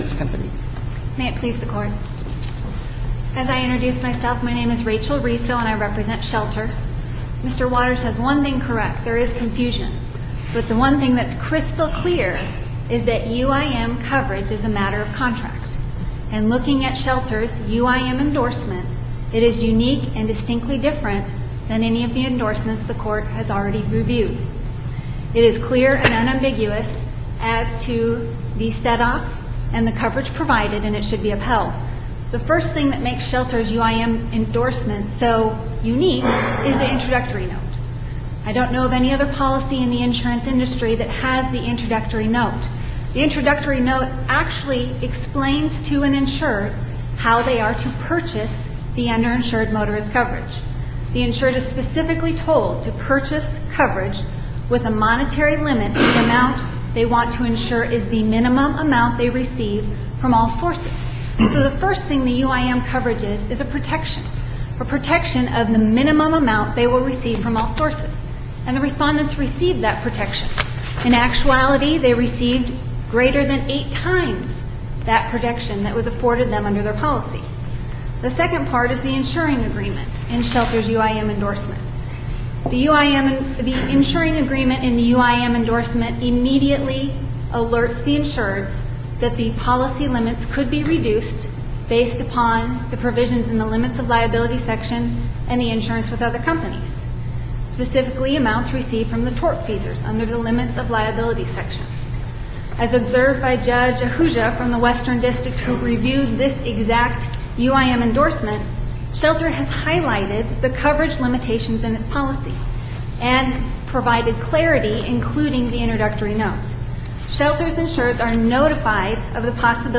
MP3 audio file of arguments in SC95843
Listen to the oral argument: SC95843.mp3